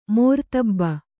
♪ mōrtabba